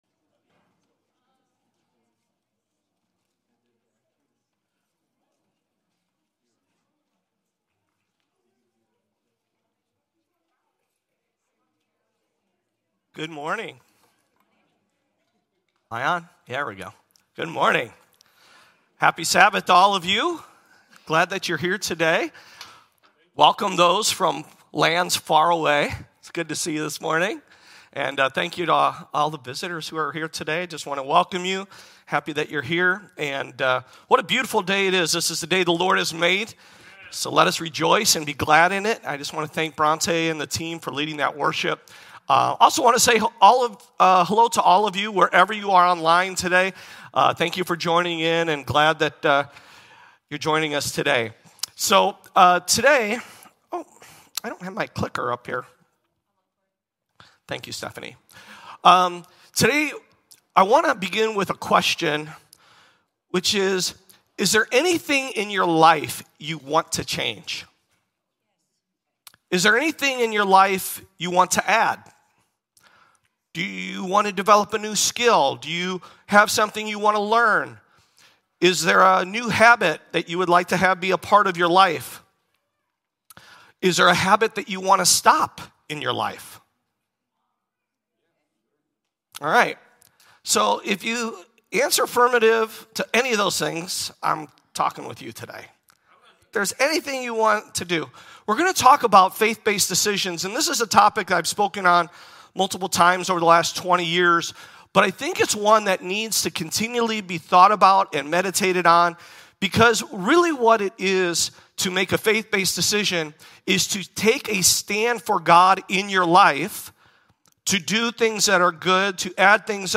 Faith-Based Decisions - Sabbath Christian Church | Rock Valley Christian Church